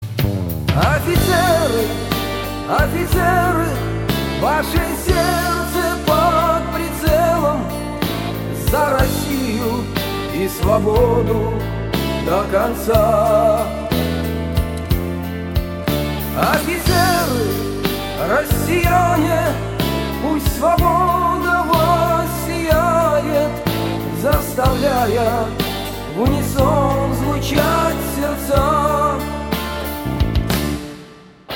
Рингтоны Военные